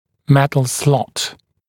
[‘metl slɔt][‘мэтл слот]металлический паз (брекета, трубки)